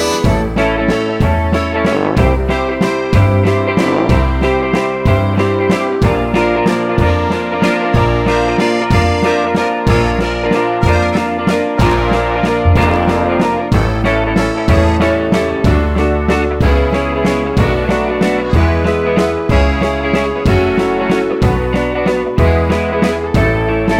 No Backing Vocals Pop (1960s) 2:06 Buy £1.50